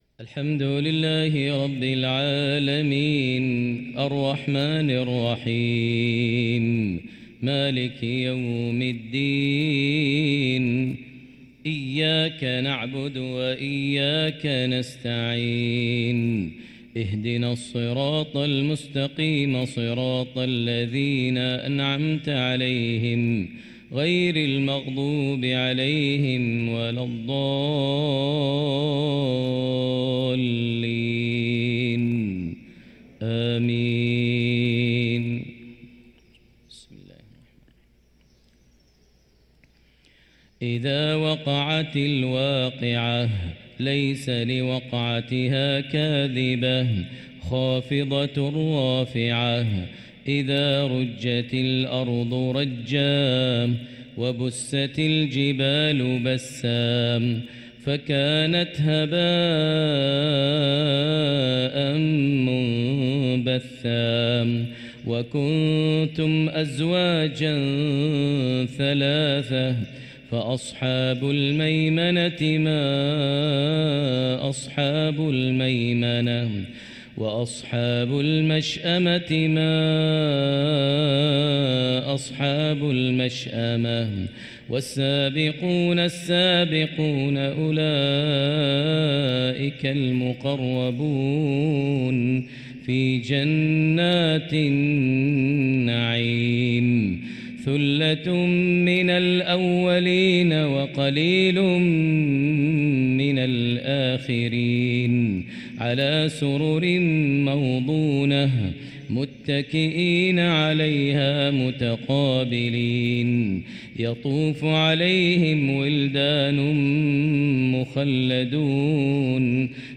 صلاة الفجر للقارئ ماهر المعيقلي 3 رجب 1445 هـ
تِلَاوَات الْحَرَمَيْن .